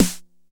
Index of /90_sSampleCDs/Roland - Rhythm Section/DRM_Drum Machine/SNR_Cheese Snrs
SNR 909 SN.wav